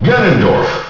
The announcer saying Ganondorf's name in Super Smash Bros. Melee.
Ganondorf_Announcer_SSBM.wav